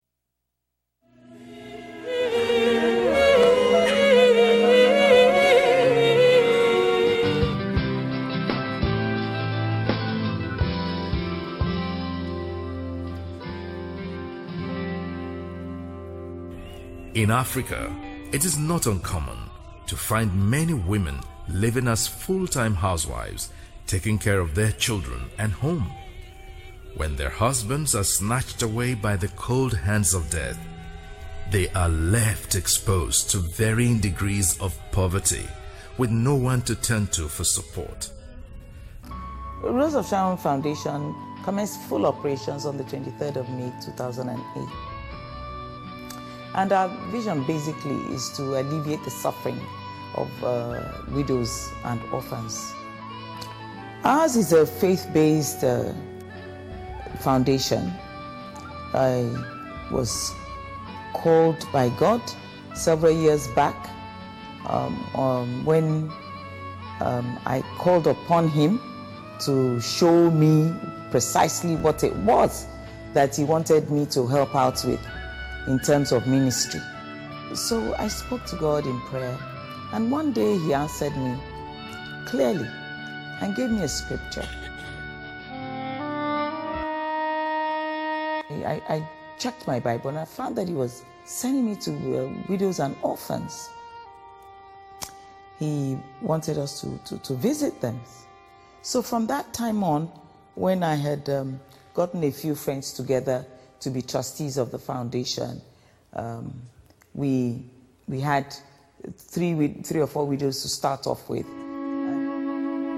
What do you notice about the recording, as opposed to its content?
Innovation is a new way of doing things that makes life better for others and it all starts with your thinking. Let the speakers from the 2014 Missions and Marketplace Conference help you to renew your mind to fresh ideas and new ways of doing business or ministry.